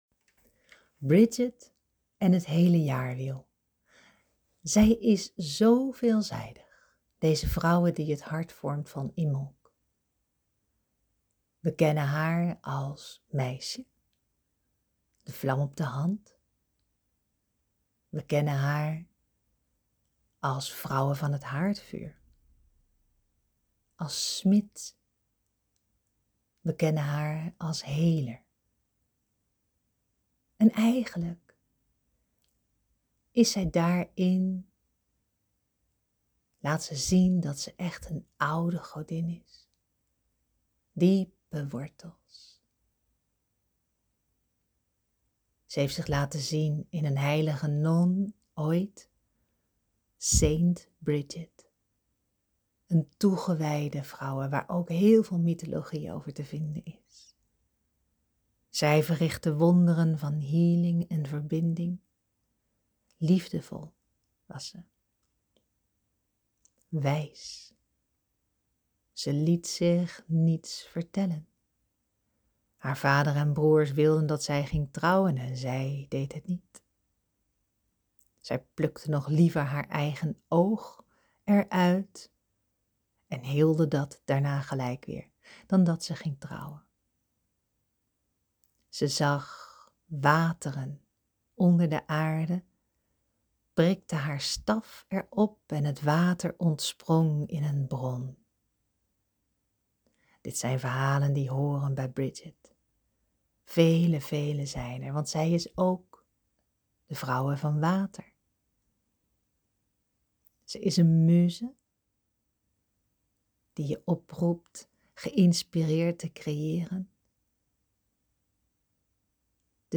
Hier kun je de meditatiereis met Brigid luisteren. deze duurt ongeveer 35 minuten, en speelt af op de website.
de-Cirkel-rond-met-Brigid-geleide-meditatie.mp3